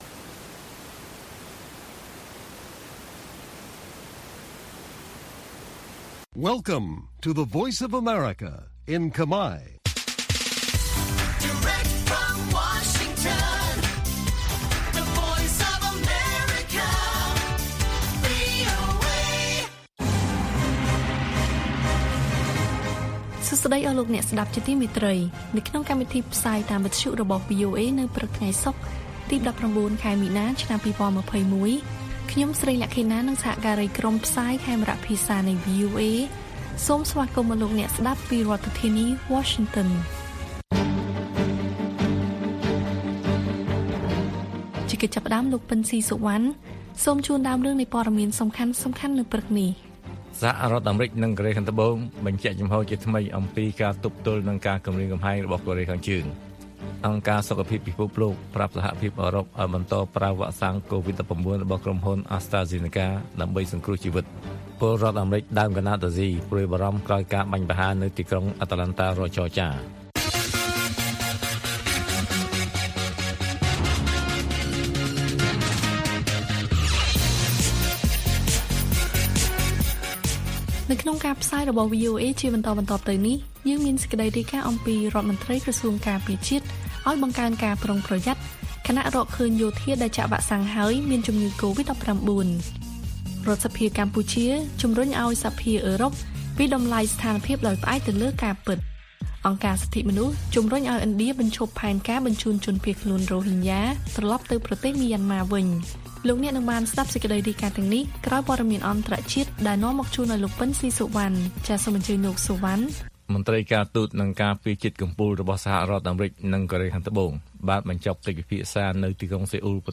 ព័ត៌មានពេលព្រឹក៖ ១៩ មីនា ២០២១